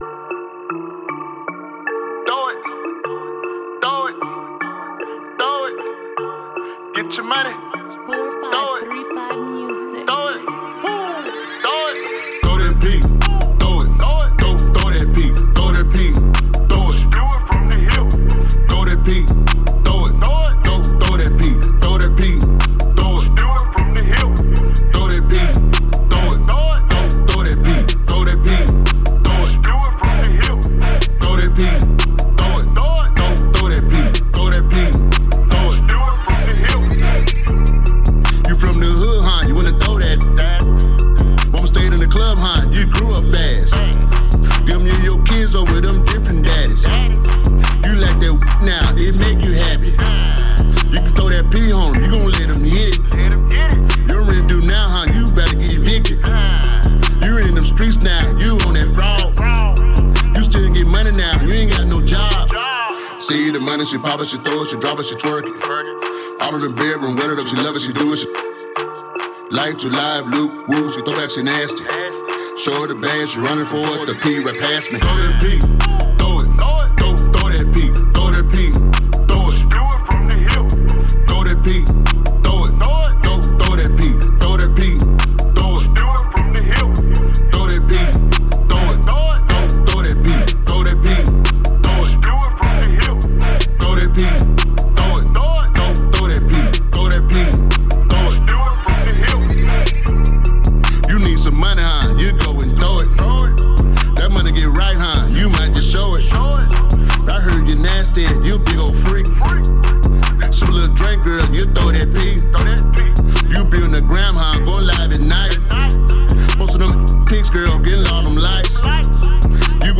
raunchy club banger